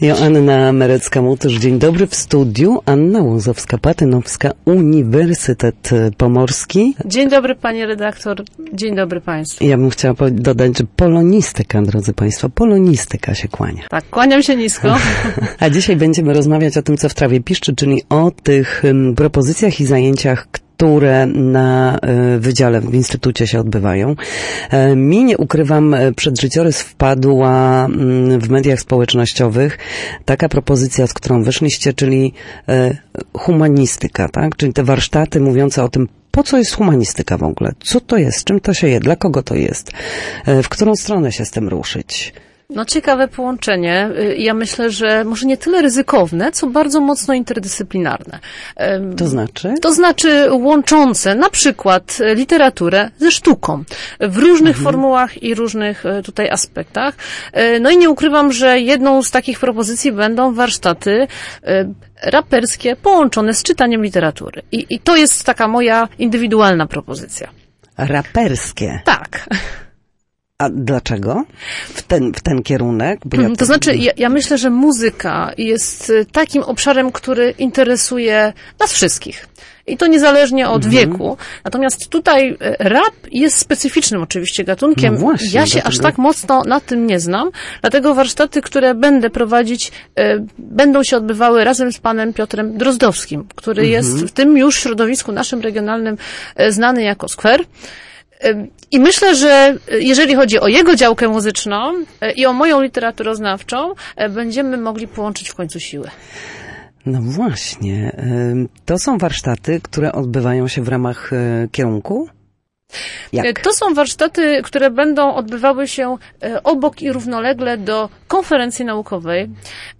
Gościem Studia Słupsk